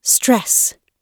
Stress [strɛs]
stress__gb_2.mp3